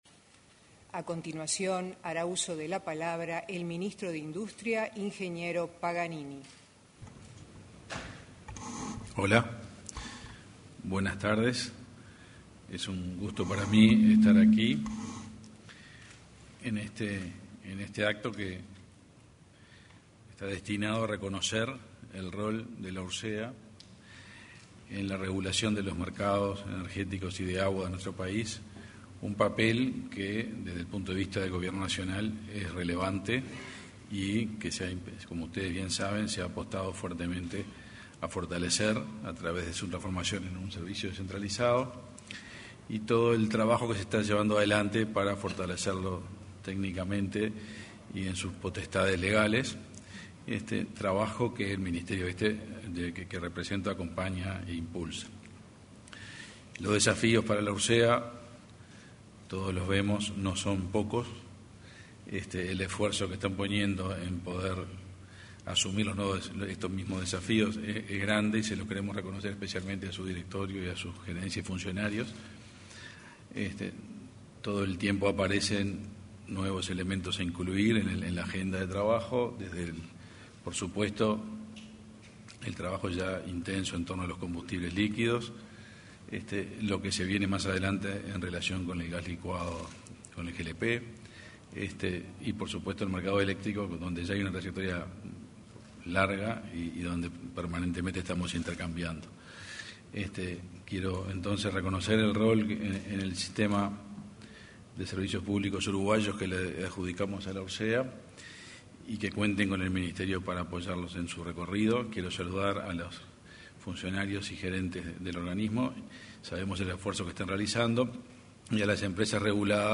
Celebración del aniversario de la Unidad Reguladora de Servicios de Energía y Agua (Ursea) 13/12/2021 Compartir Facebook X Copiar enlace WhatsApp LinkedIn Con motivo del 19.° aniversario de creación de la Unidad Reguladora de Servicios de Energía y Agua (Ursea), este lunes 13 de diciembre se realizó un acto de conmemoración en el auditorio de la Torre Ejecutiva. Se expresaron el ministro de Industria, Energía y Minería, Omar Paganini, y la presidenta de Ursea, Silvana Romero.